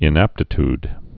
(ĭn-ăptĭ-td, -tyd)